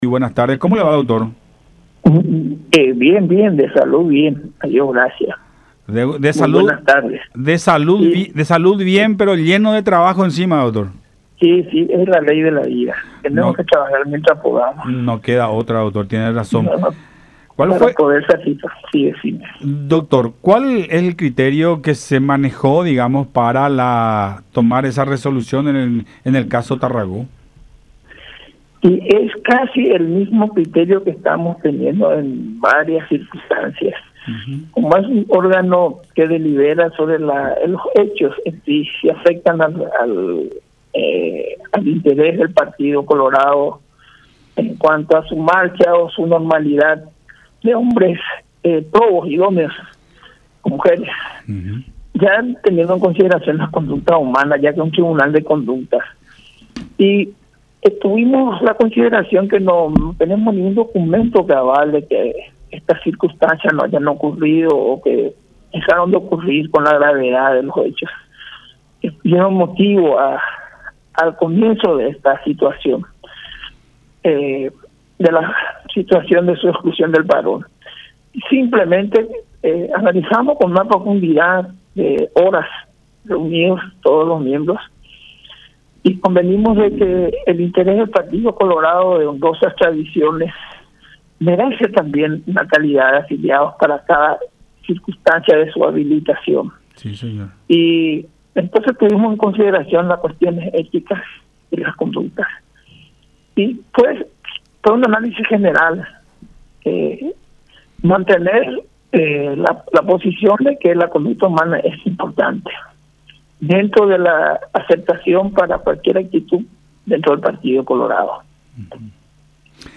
en conversación con Buenas Tardes La Unión por Unión TV.